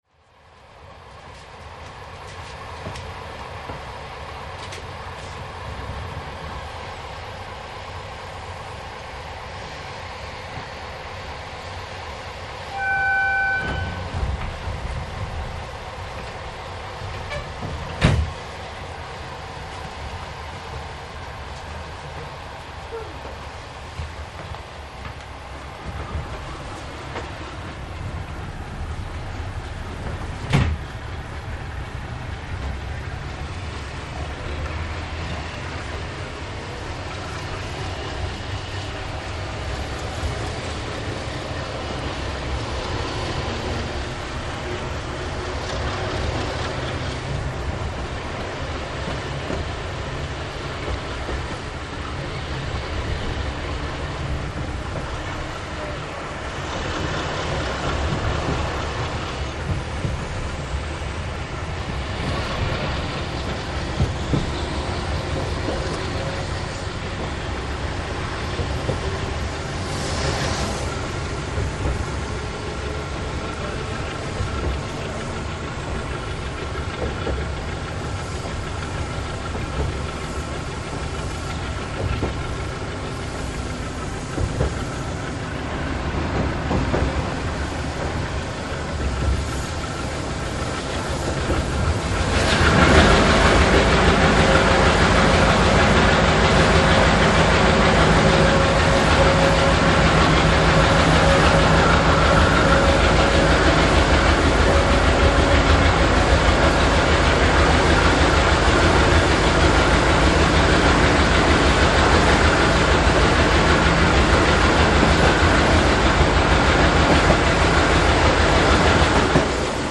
紀伊長島を出て難所の荷坂峠にさしかかる所まで。発車後しばらくしてから勢いよく加速。モーター音とボンボン弾むような音が共演して
何ともうるさい？機関車です。